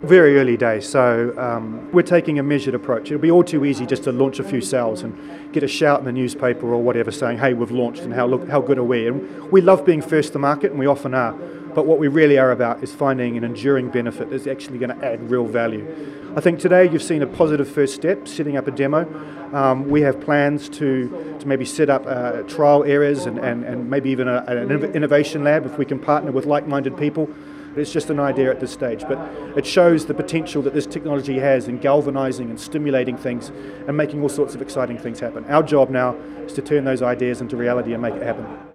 Manx Telecom hosts promo event